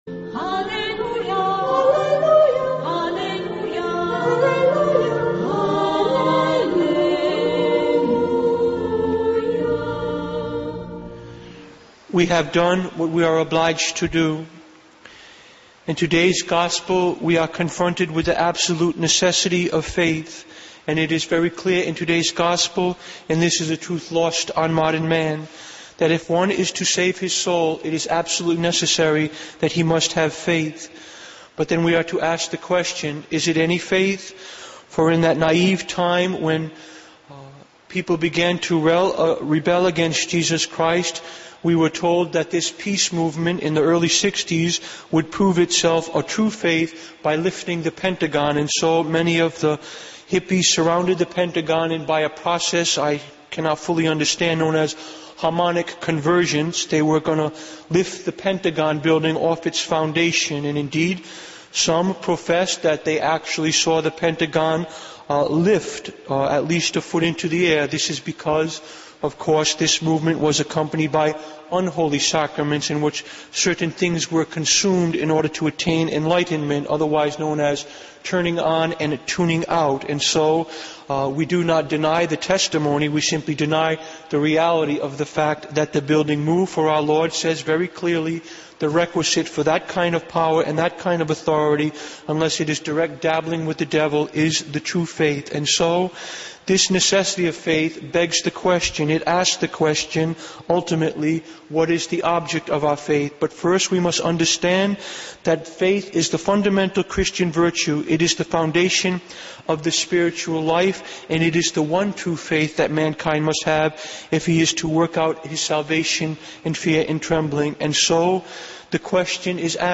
Homilies #184 - We Must Have Faith(43min) >>> Play ?